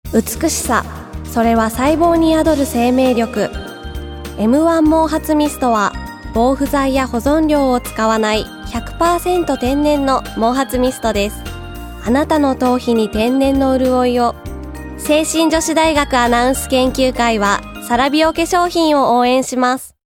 どれも清々しいお声で、皆さんの笑顔が浮かぶステキなＣＭです！
聖心女子大学アナウンス研究会による「ラジオCM」